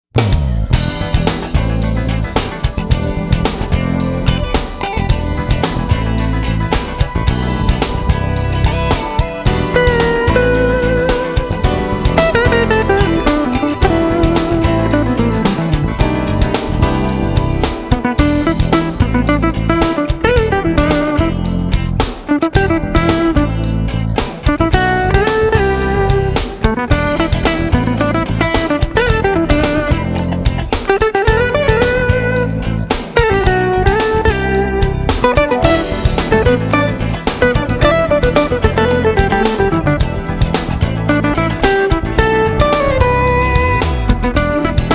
We feature various smooth jazz artists among others;